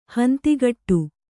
♪ hantigaṭṭu